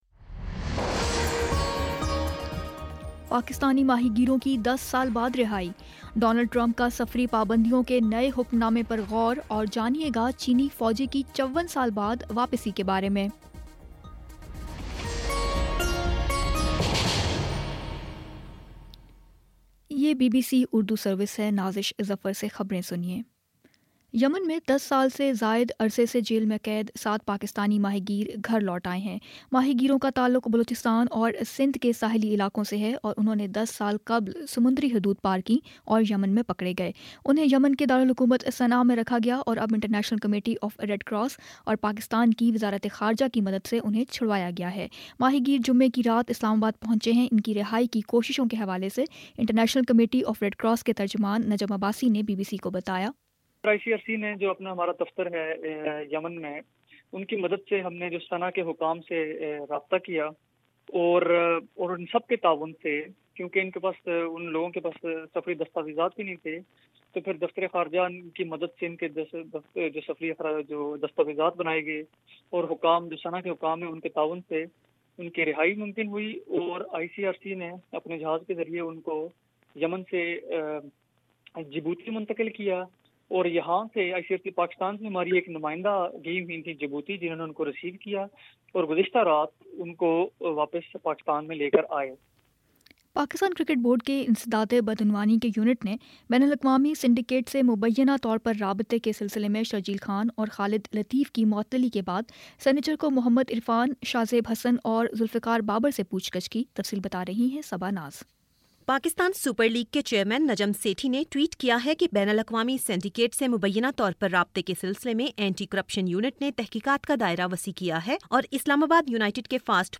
فروری 11 : شام پانچ بجے کا نیوز بُلیٹن